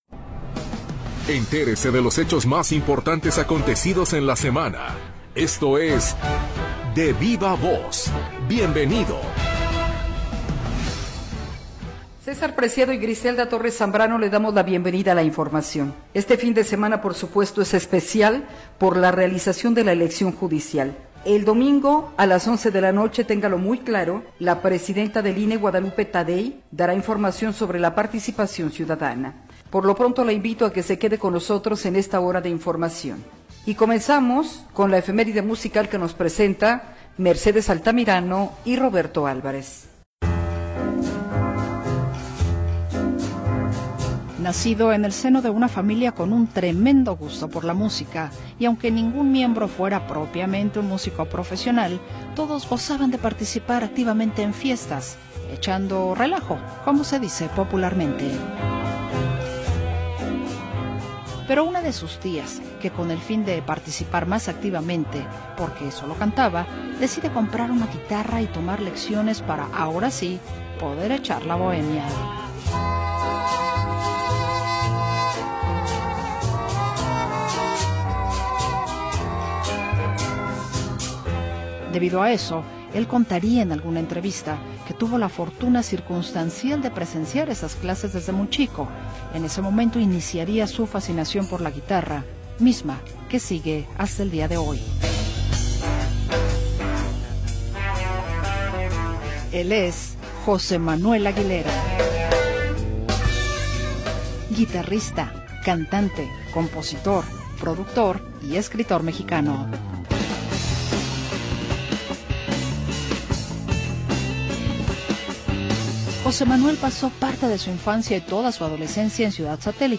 Lo mejor de las entrevistas de la semana en Radio Metrópoli.